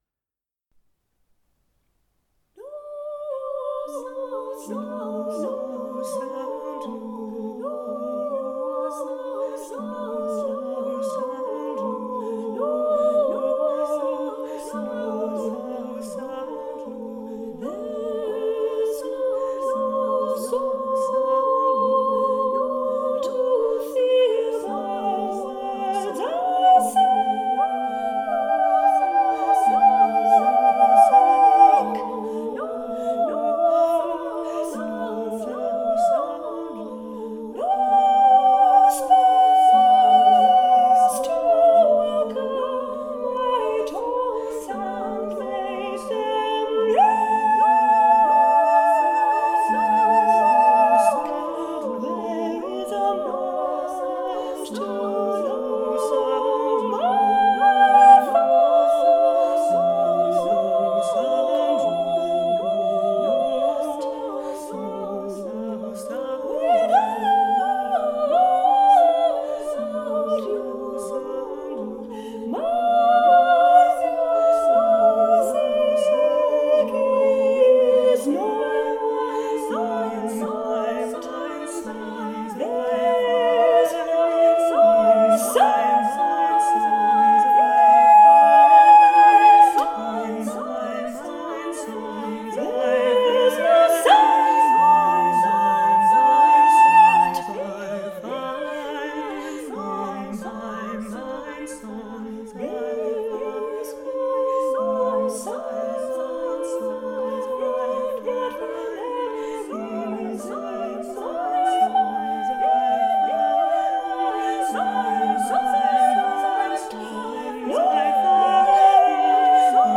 multi-track